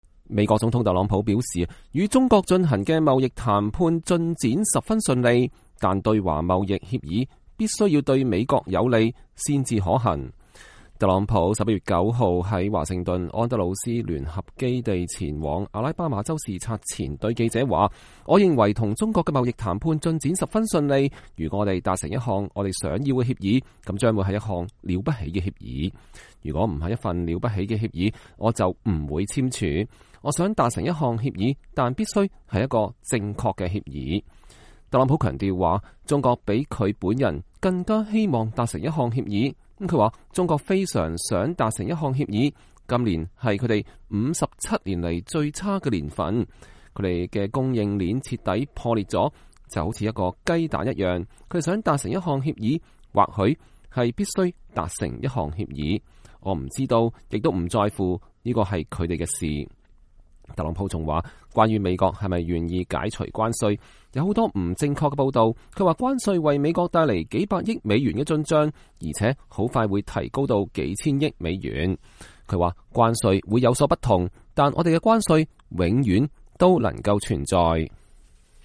美國總統特朗普11月9日在安德魯斯聯合基地搭乘空軍一號前往阿拉巴馬州視察前對記者發表講話。